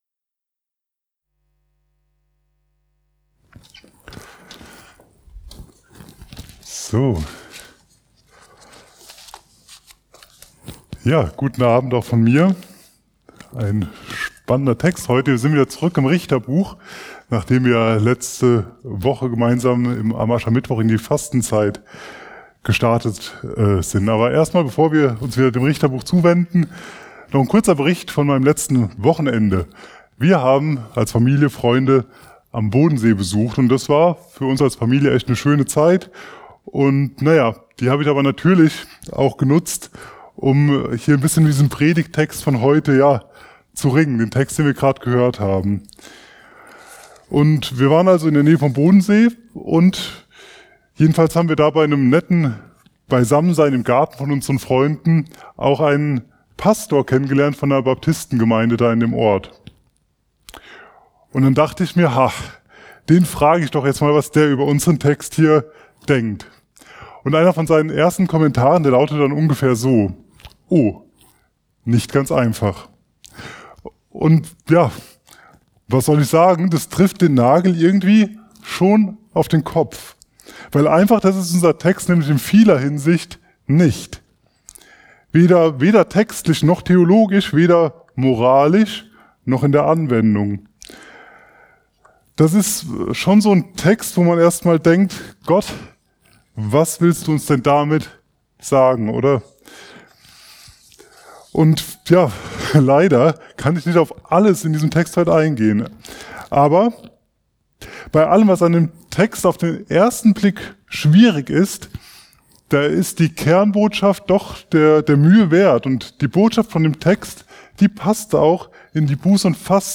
Vom Götzendienst zur Freiheit ~ Mittwochsgottesdienst Podcast